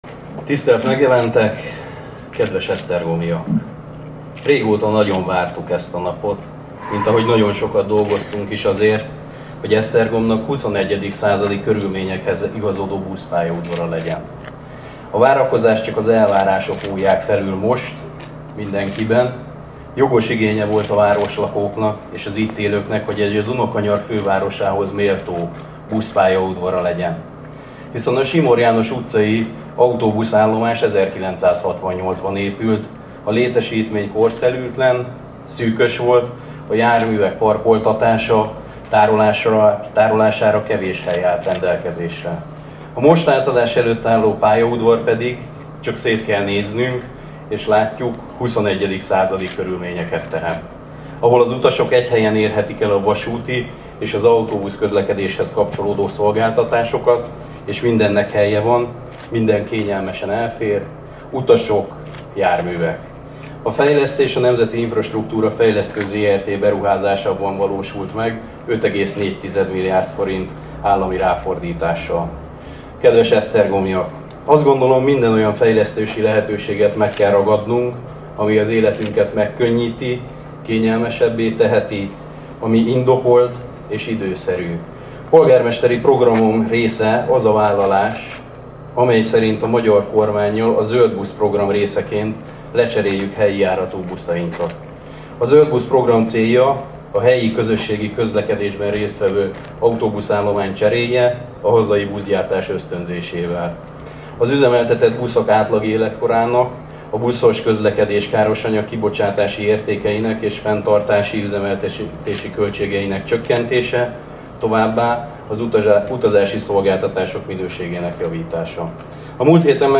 Szűk körben átadták Esztergom új autóbuszpályaudvarát
Hernádi Ádám beszéde: